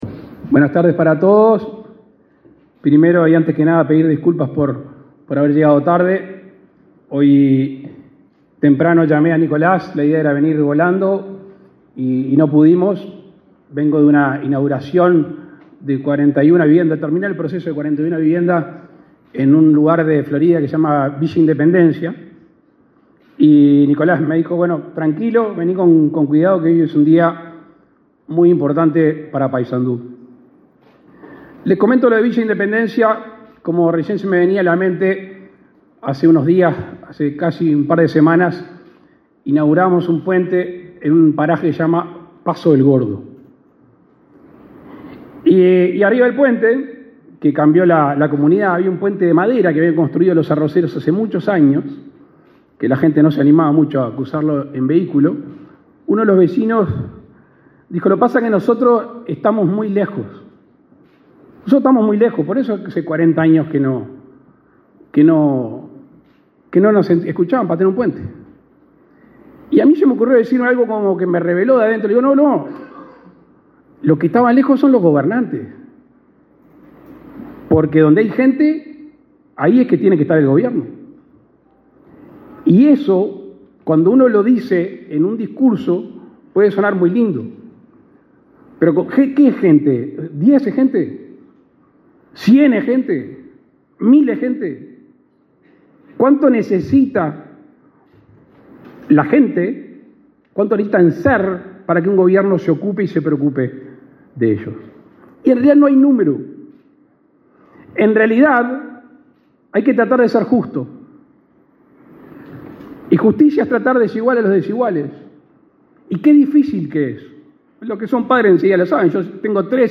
Palabras del presidente de la República, Luis Lacalle Pou